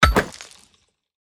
axe-mining-stone-1.ogg